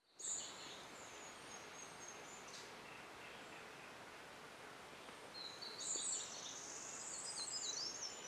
Pitiayumí (Setophaga pitiayumi)
Nombre en inglés: Tropical Parula
Fase de la vida: Adulto
Localidad o área protegida: Reserva Privada y Ecolodge Surucuá
Condición: Silvestre
Certeza: Vocalización Grabada